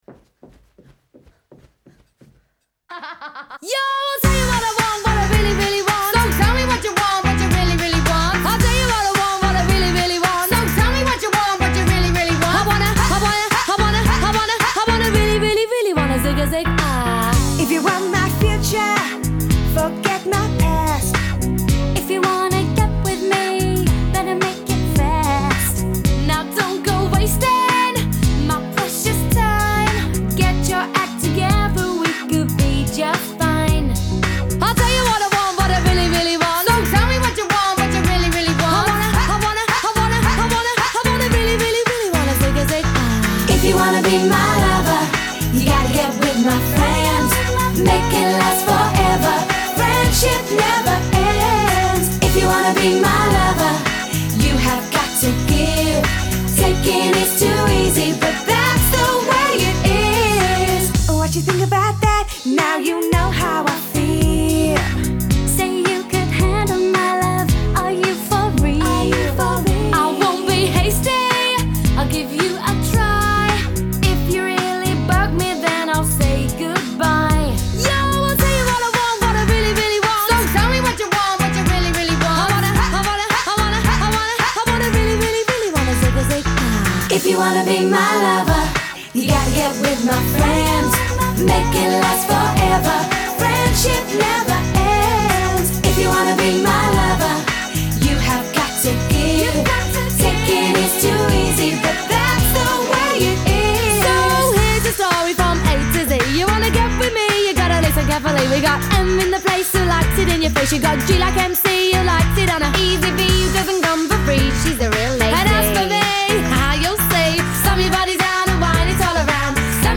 Pop 90er